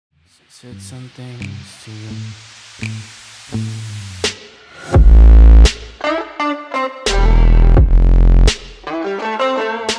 twitchhitbox-followdonation-sound-7_YGr6z5d.mp3